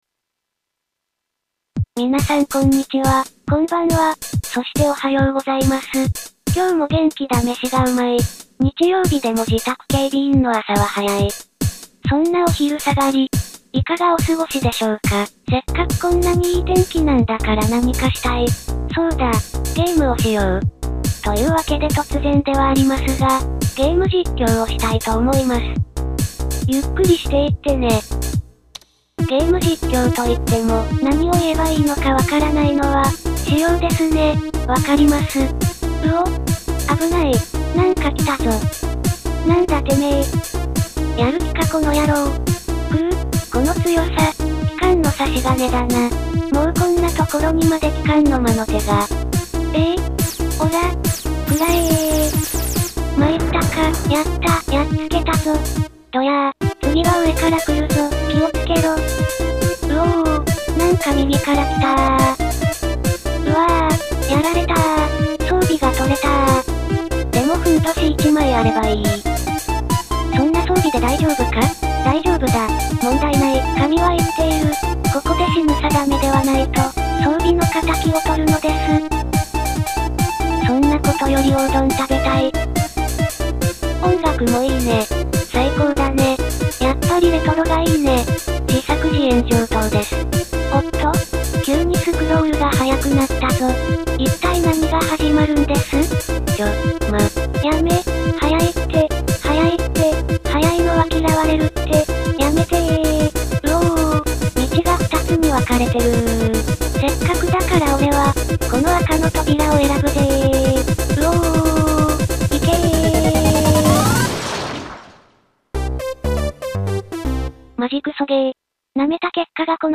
なんだかすいません、新曲です。ネタ曲的新曲なんです！
そんな訳でゆっくりがゲームをしながら実況をしているというでした。